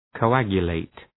Προφορά
{kəʋ’ægjə,leıt}